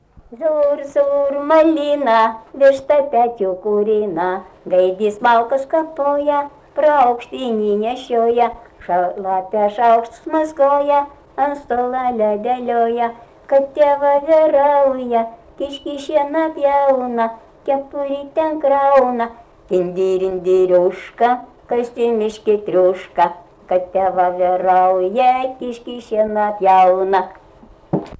smulkieji žanrai
Bagdoniškis
vokalinis